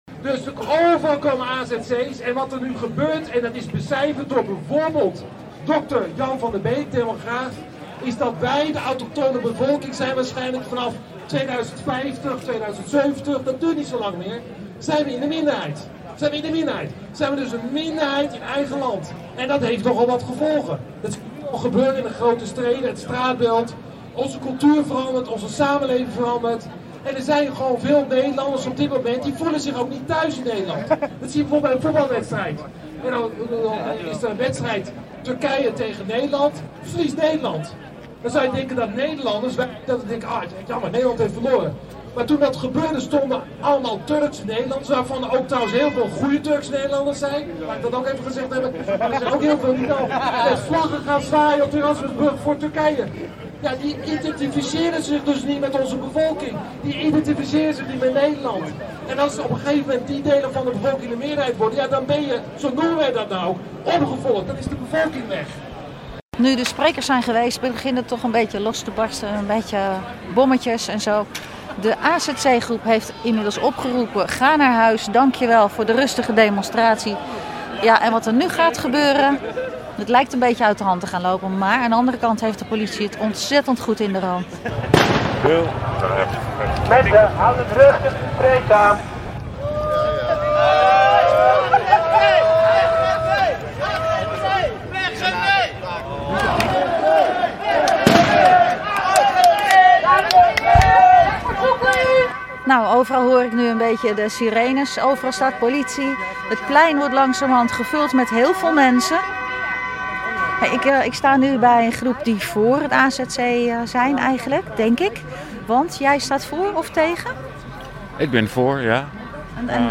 Audioreportage-Demonstratie-Uithoorn-.mp3